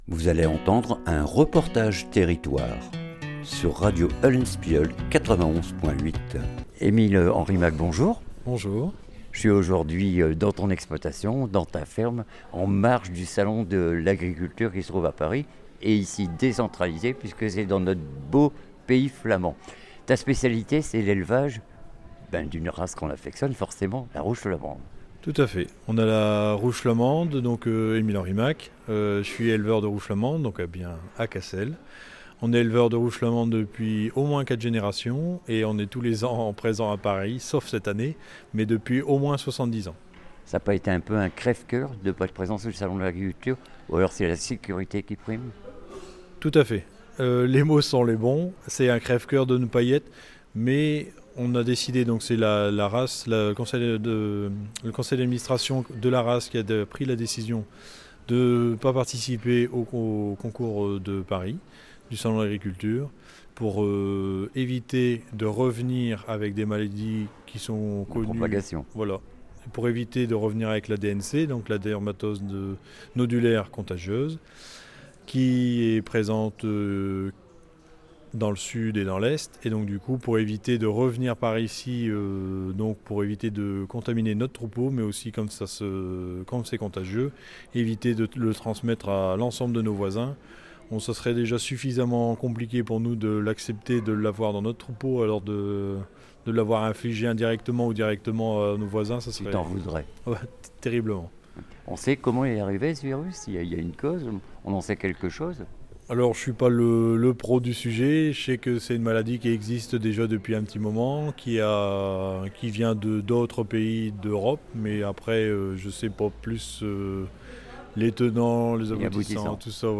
REPORTAGE TERRITOIRE FERME DU COU COU
JOURNEE PORTE OUVERTE DPT DU NORD FERME DU COU COU CASSEL